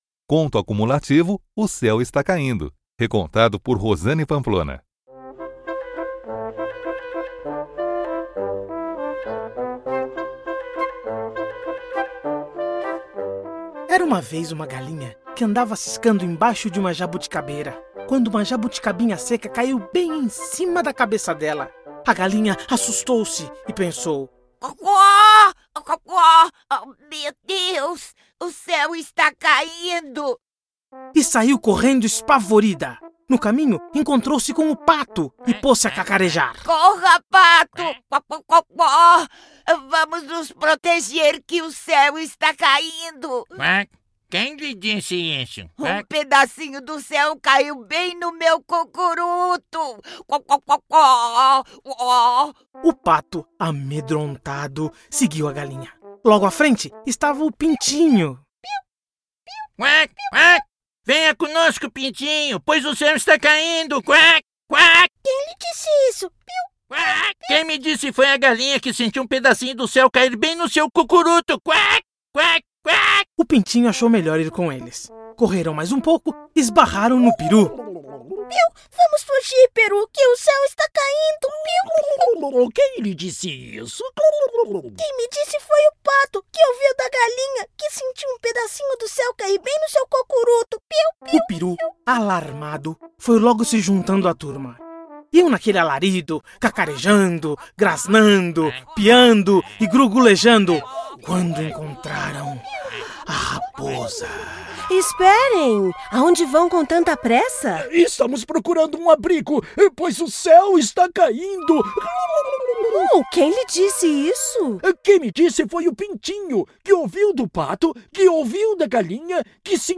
Conto acumulativo "O céu está caindo!"